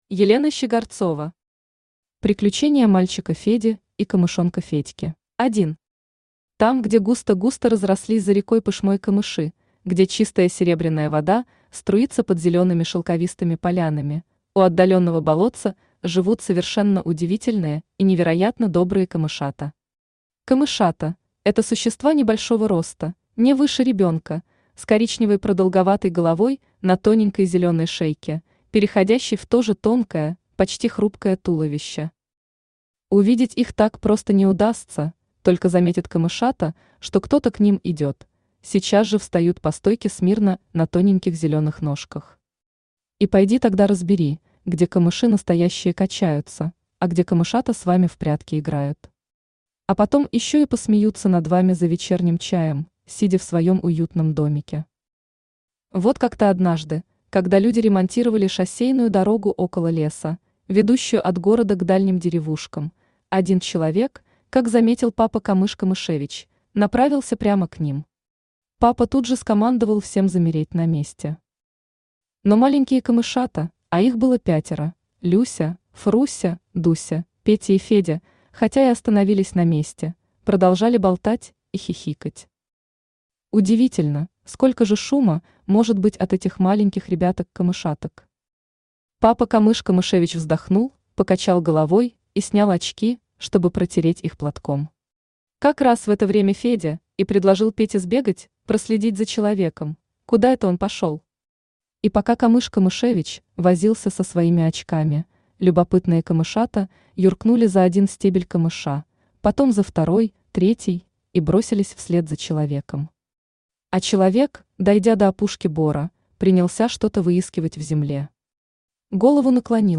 Аудиокнига Приключения мальчика Феди и камышонка Федьки | Библиотека аудиокниг
Aудиокнига Приключения мальчика Феди и камышонка Федьки Автор Елена Николаевна Щигорцова Читает аудиокнигу Авточтец ЛитРес.